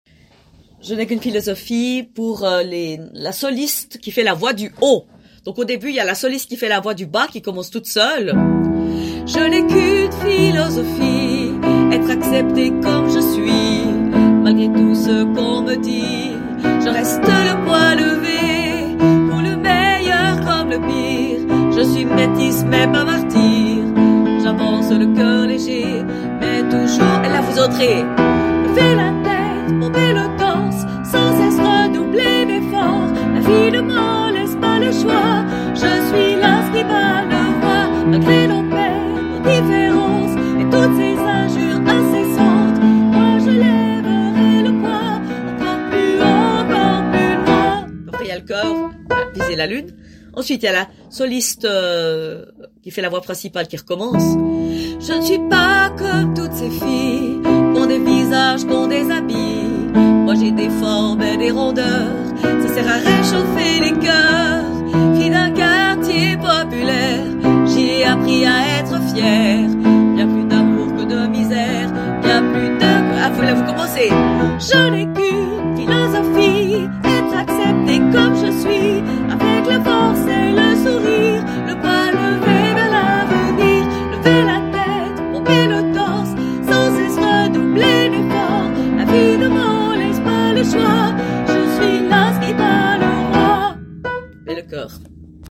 solo voix haute